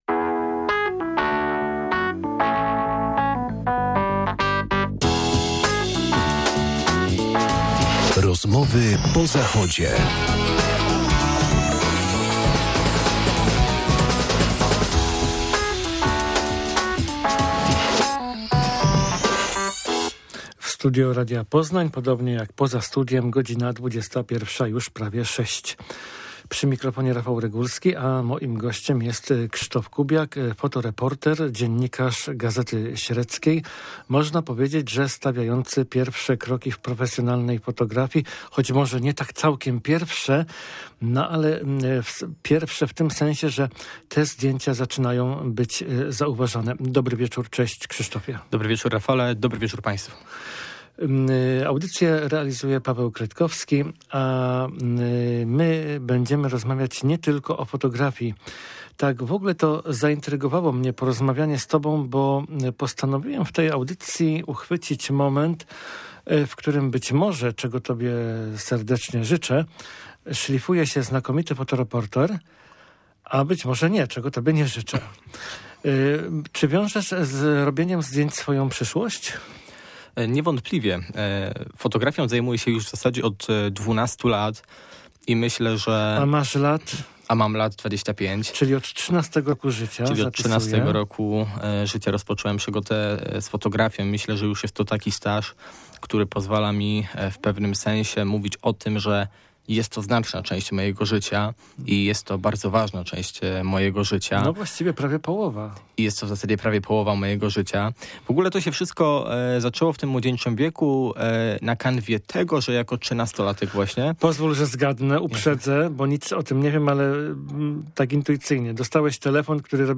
- to próbujemy ustalić w rozmowie z nim.